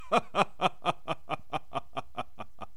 evil man laughing
evil laugh laughing laughter mad male man sound effect free sound royalty free Funny